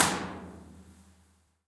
Harbor Entrance Control Post
Concrete, wood.
Download this impulse response (right click and “save as”)
HarborEntranceControlPost.wav